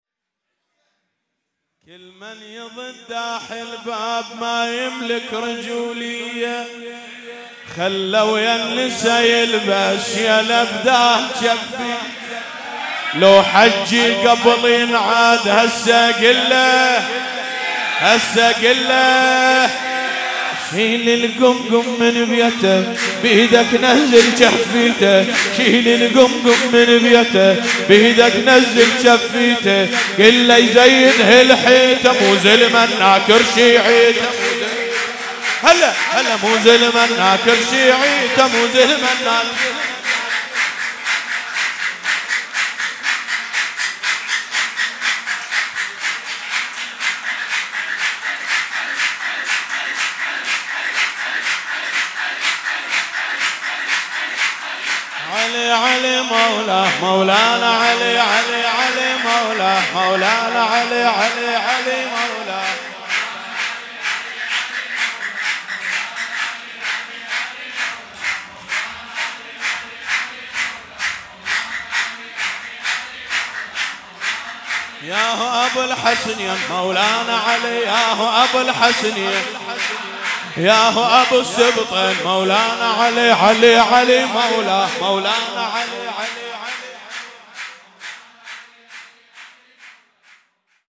سرود عربی2|ولادت حضرت زینب کبری(س)